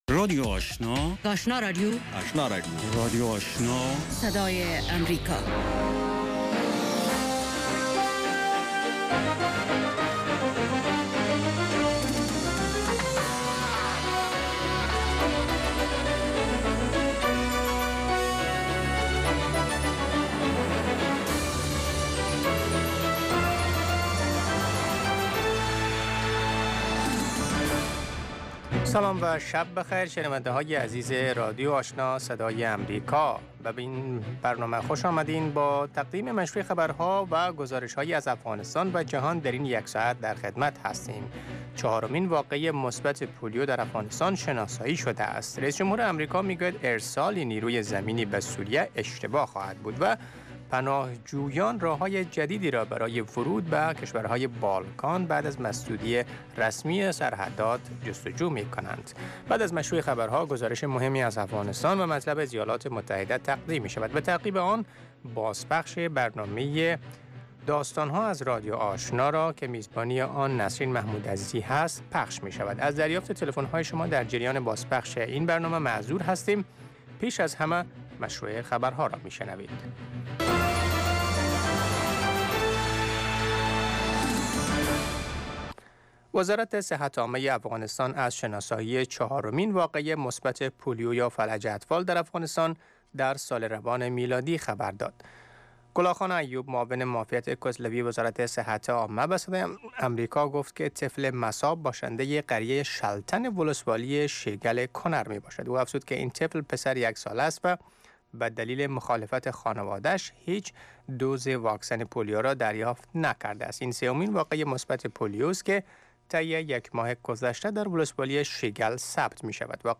سومین برنامه خبری شب